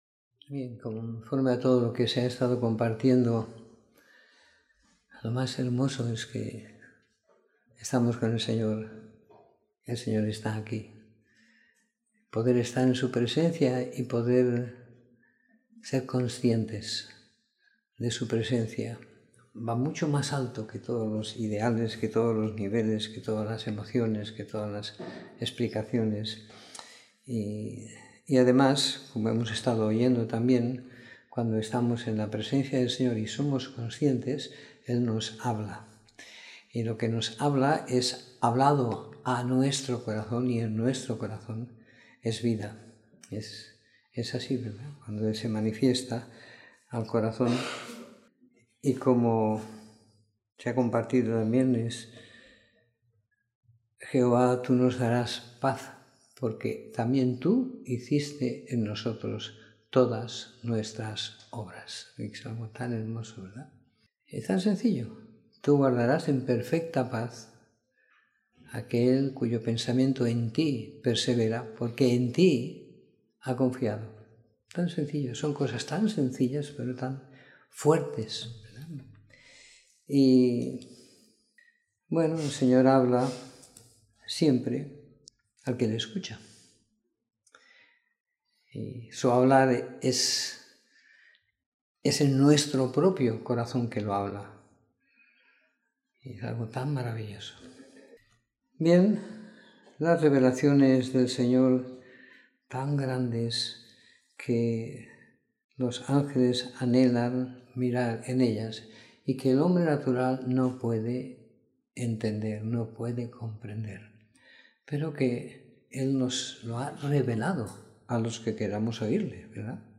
Domingo por la Tarde . 18 de Febrero de 2018